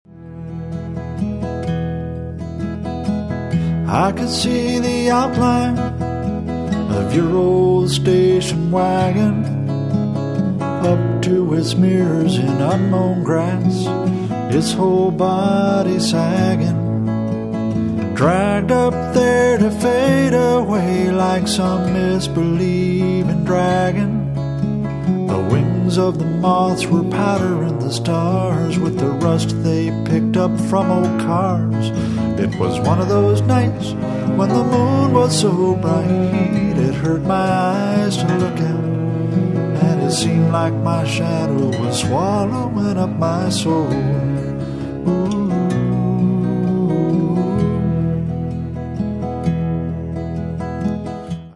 folk music